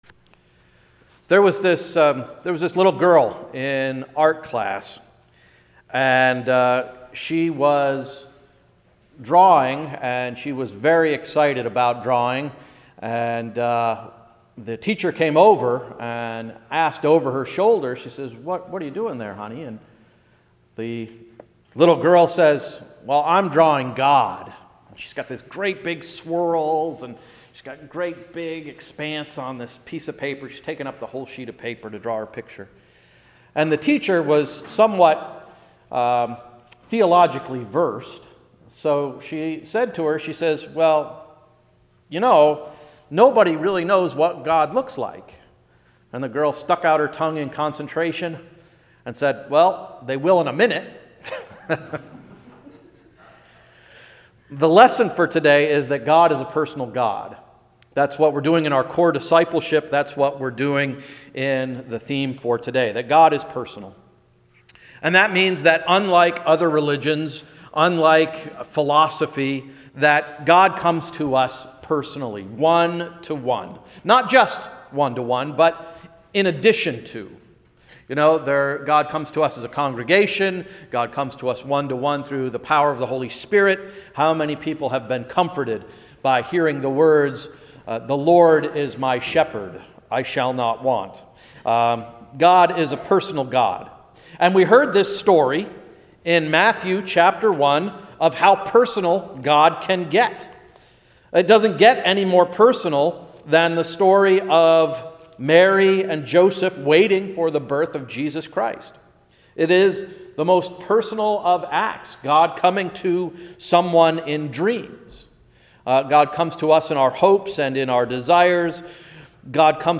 Sermon of January 4, 2015–“Day Dreaming”